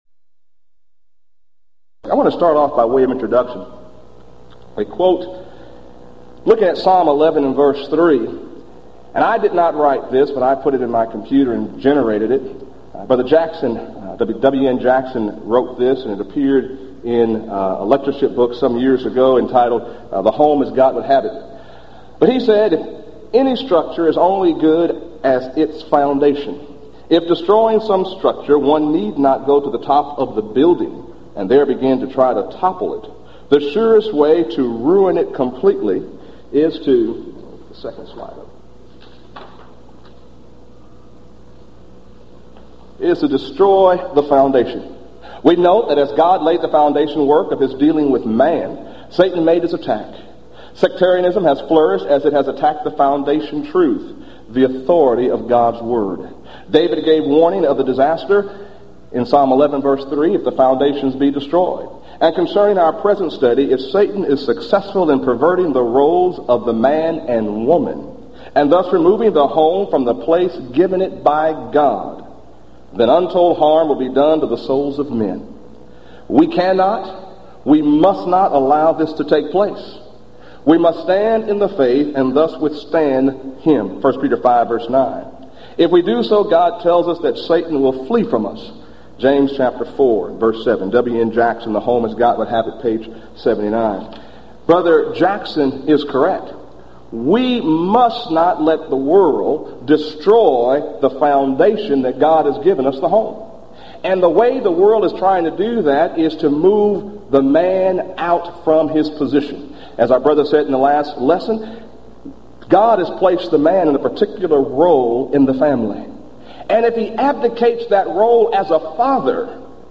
Event: 1996 Gulf Coast Lectures
lecture